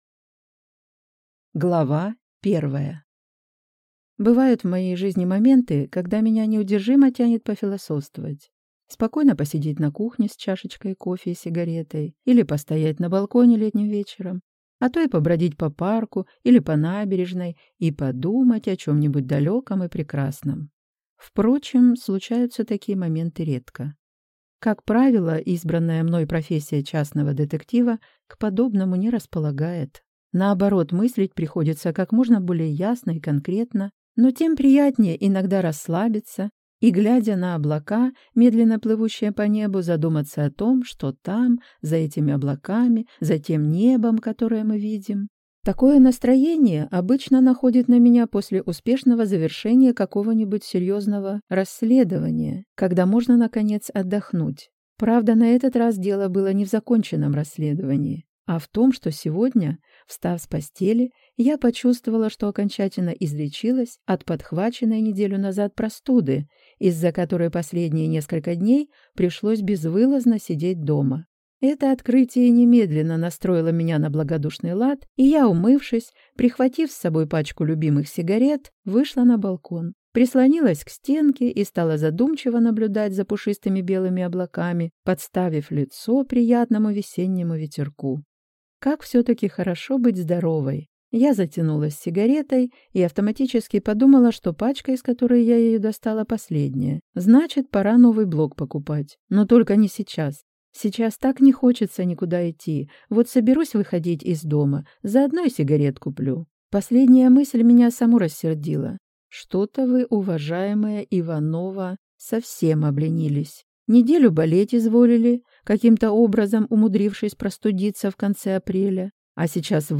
Аудиокнига Чище воды, острее ножа | Библиотека аудиокниг